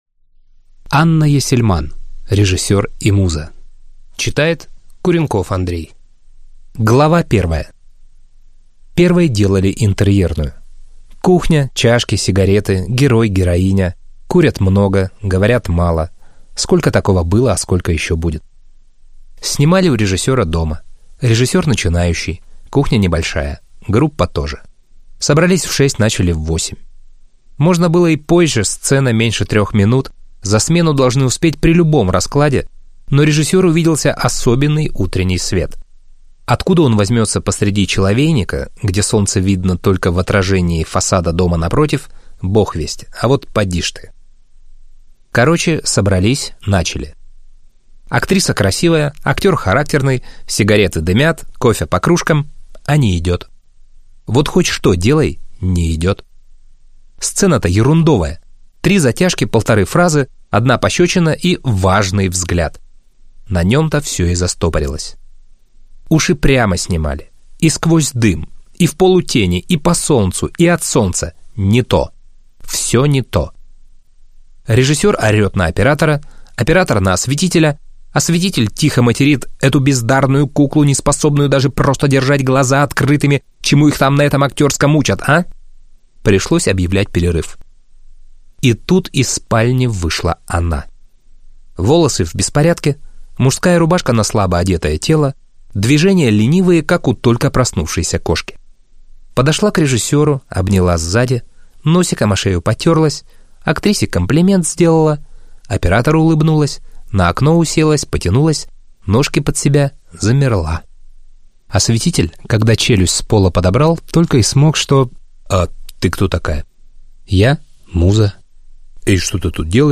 Аудиокнига Режиссёр и Муза. История непростых отношений | Библиотека аудиокниг